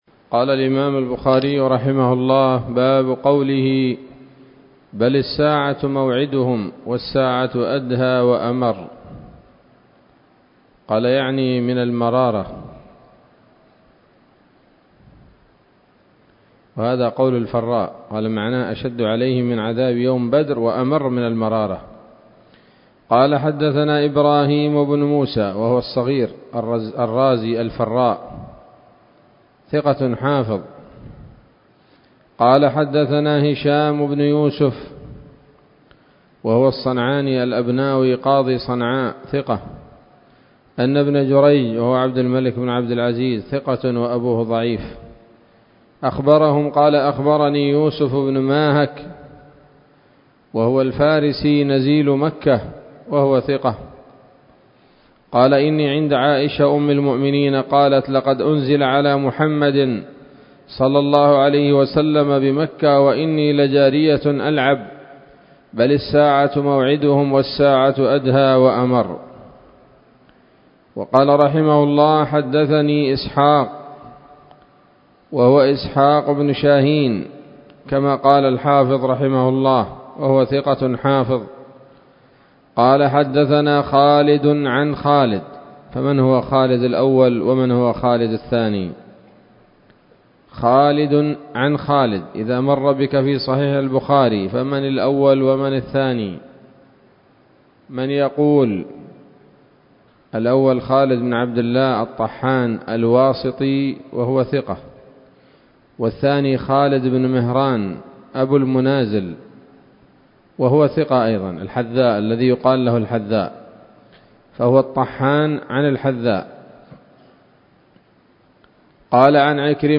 الدروس العلمية شروح الحديث صحيح الإمام البخاري كتاب التفسير من صحيح البخاري
الدرس الثامن والأربعون بعد المائتين من كتاب التفسير من صحيح الإمام البخاري